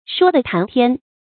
說地談天 注音： ㄕㄨㄛ ㄉㄧˋ ㄊㄢˊ ㄊㄧㄢ 讀音讀法： 意思解釋： 天上地下，無所不談。